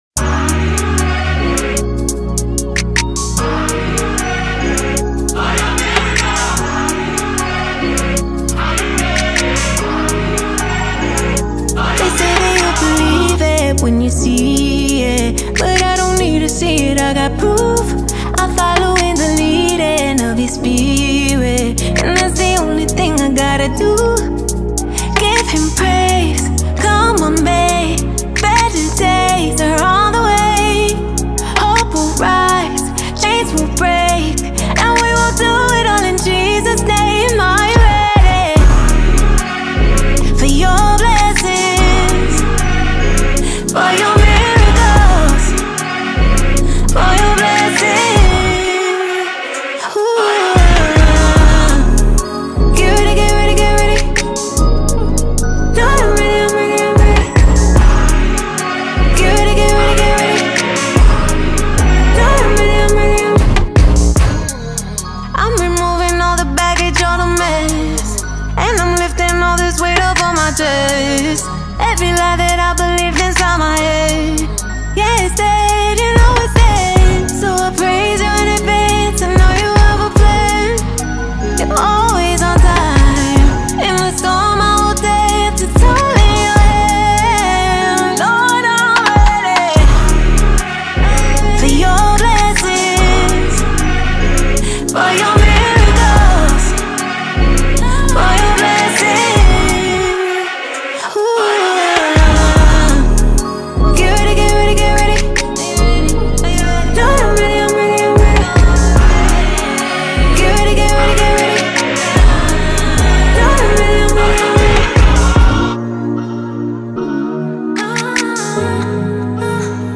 169 просмотров 99 прослушиваний 12 скачиваний BPM: 75